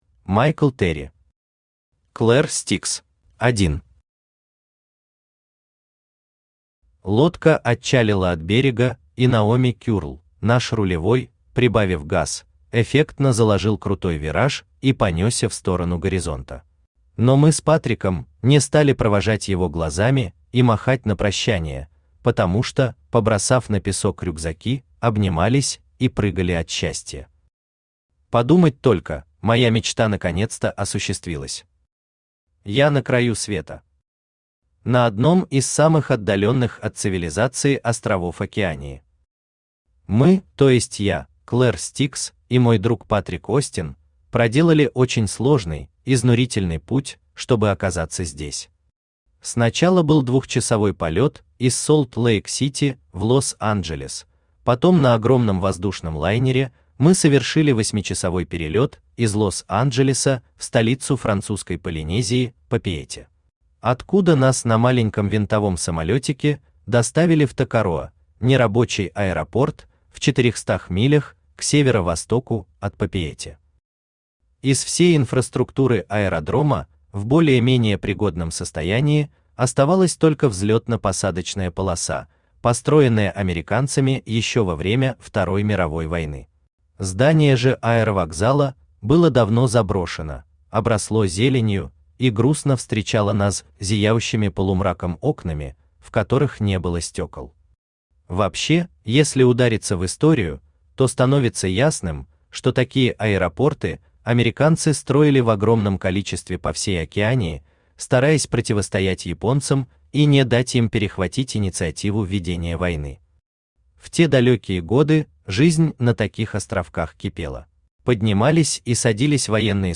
Аудиокнига Клер Стикс | Библиотека аудиокниг
Aудиокнига Клер Стикс Автор Майкл Терри Читает аудиокнигу Авточтец ЛитРес.